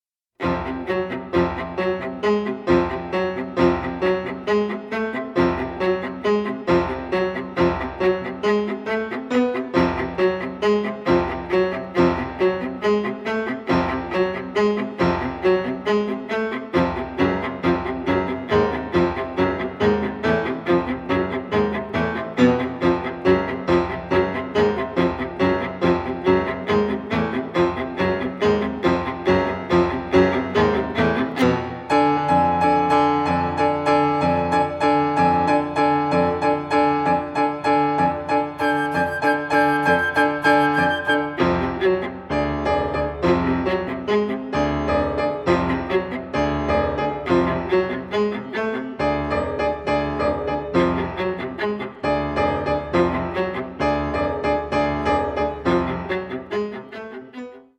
flute
viola
piano